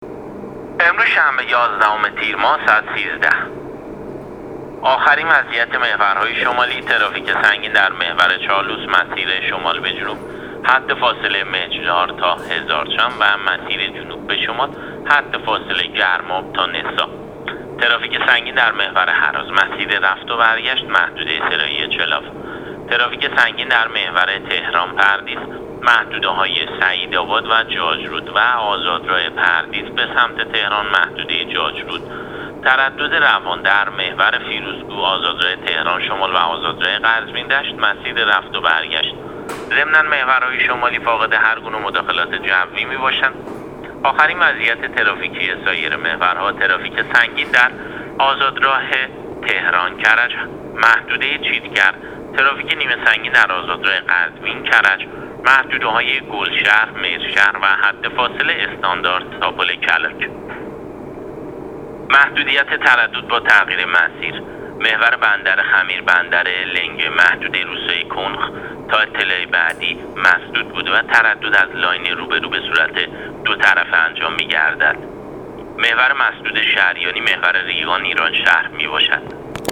گزارش رادیو اینترنتی از آخرین وضعیت ترافیکی جاده‌ها تا ساعت ۱۳ یازدهم تیر؛